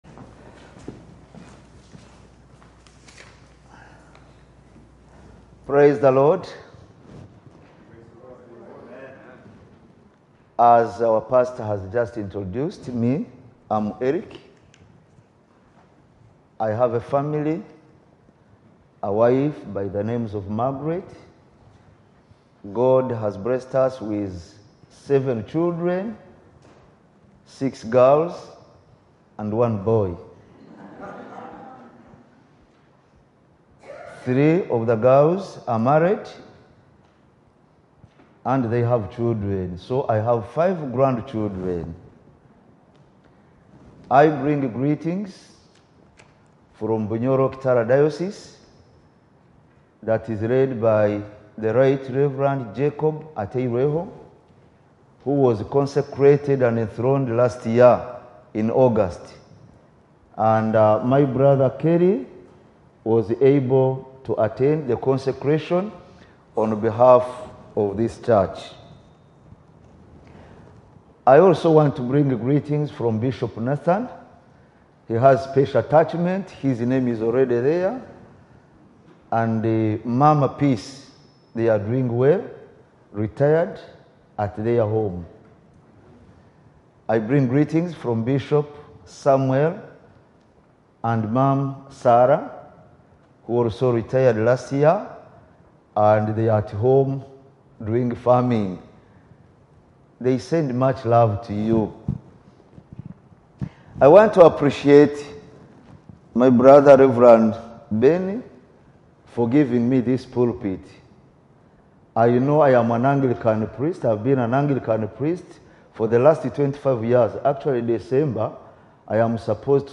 preached to us from Revelation 19 about the marriage covenant that we enter into as Christians.